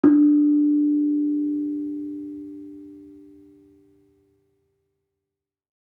Gamelan Sound Bank
Kenong-resonant-D3-f.wav